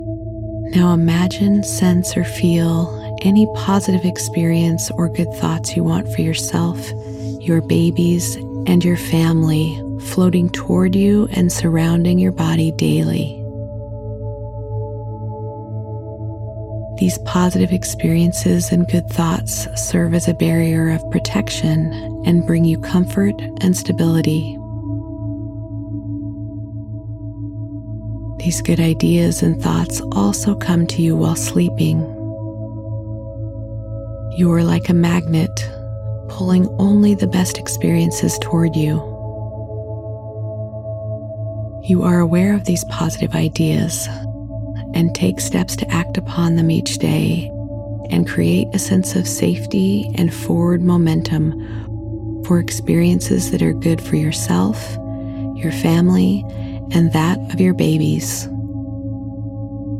It contains Releasing Your Power Centers©, Power Center Affirmations© and followed by a subliminal set of Affirmations with no “count up”, so that you can drift off to sleep with music only at the end of the recording(not to be listened to or used when operating any automobile or machinery).
(Multiples)Releasing Your Power Centers_Affirmations_Subliminal_Night-sample.mp3